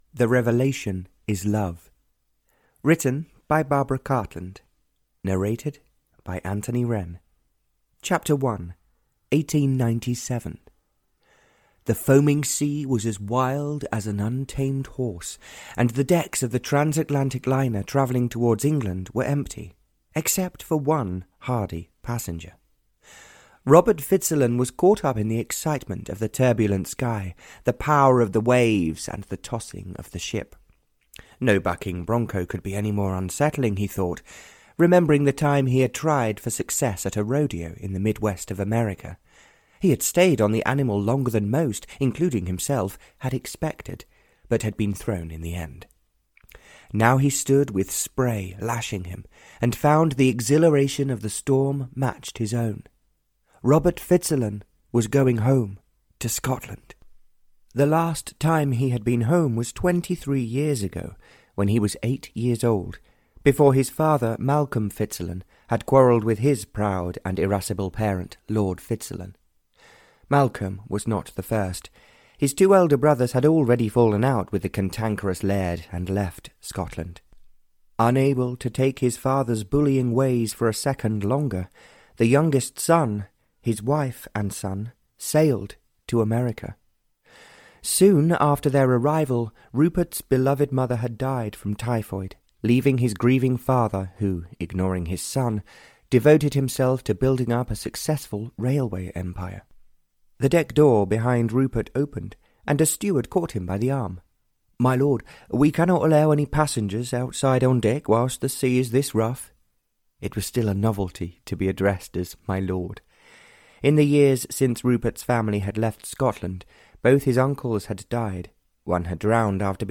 The Revelation is Love (Barbara Cartland s Pink Collection 73) (EN) audiokniha
Ukázka z knihy